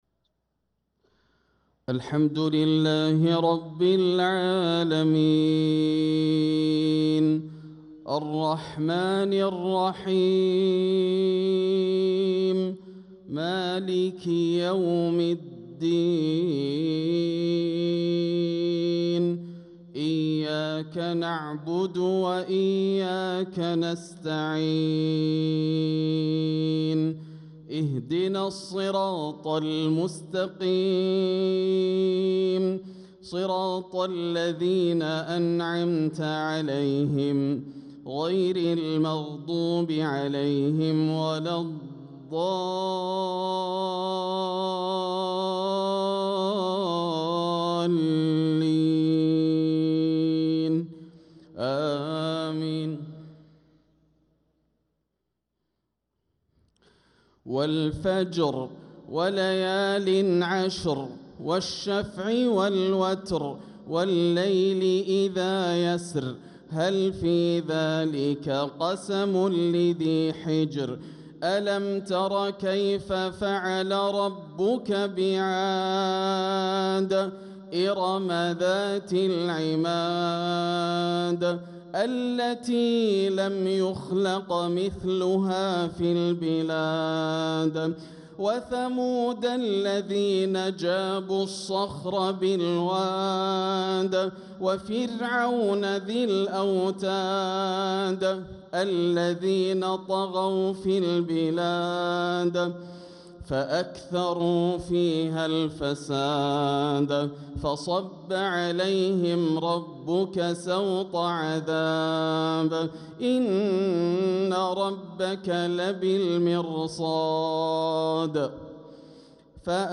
صلاة العشاء للقارئ ياسر الدوسري 22 ربيع الآخر 1446 هـ